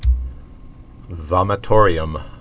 (vŏmĭ-tôrē-əm)